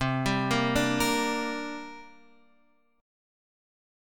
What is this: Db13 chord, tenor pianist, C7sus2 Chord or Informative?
C7sus2 Chord